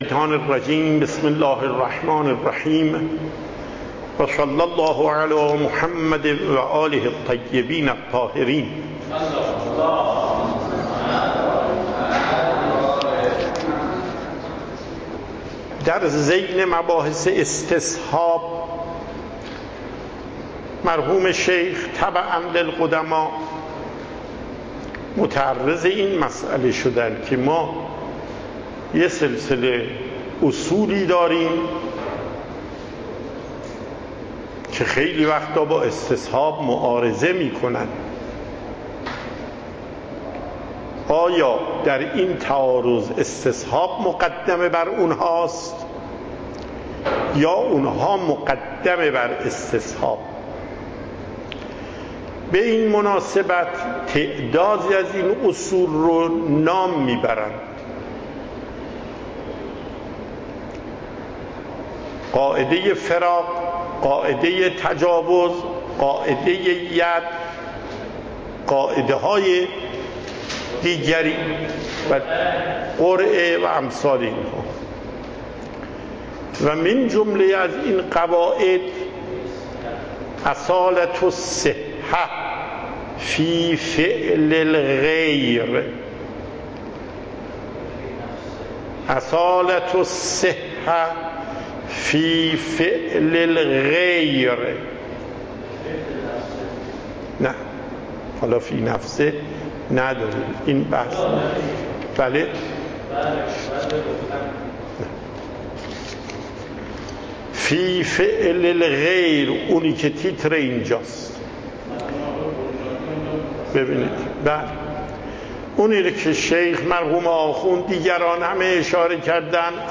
صوت و تقریر درس